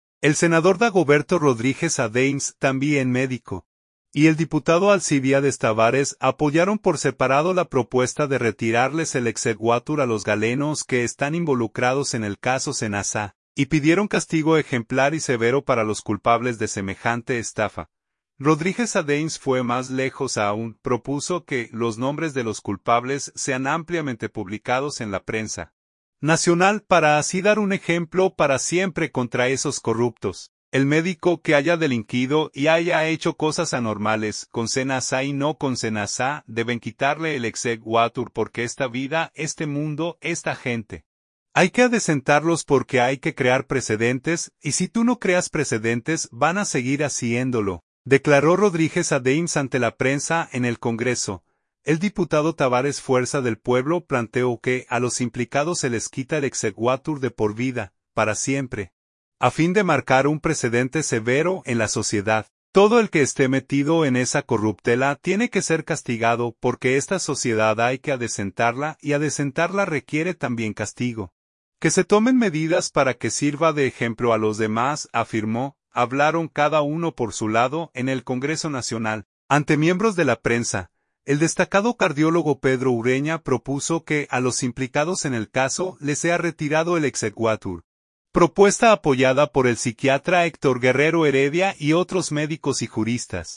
“El médico que haya delinquido y haya hecho cosas anormales, con SeNaSa y no con SeNaSa, deben quitarle el exequátur porque esta vida, este mundo, esta gente, hay que adecentarlos porque hay que crear precedentes, y si tú no creas precedentes van a seguir haciéndolo”, declaró Rodríguez Adames ante la prensa, en el Congreso.
Hablaron cada uno por su lado, en el Congreso Nacional, ante miembros de la prensa.